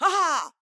VO_LVL3_EVENT_Aha echec_03.ogg